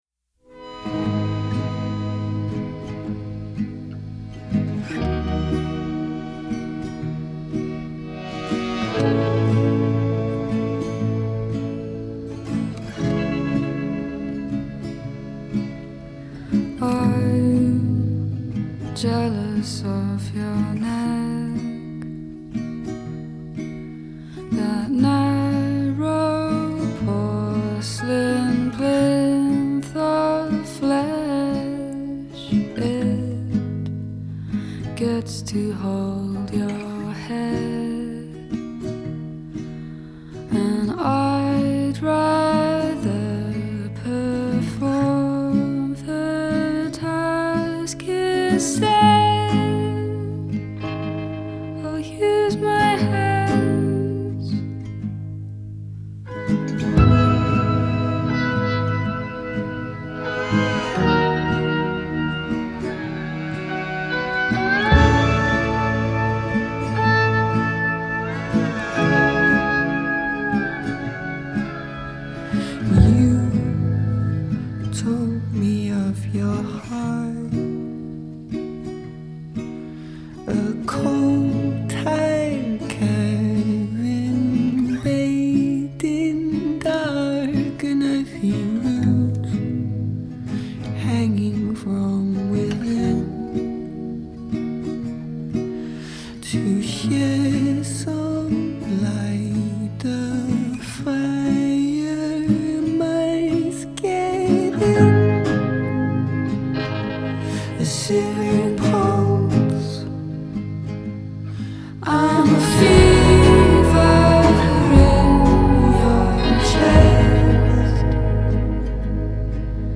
quiet, but elusively sturdy
acoustic guitar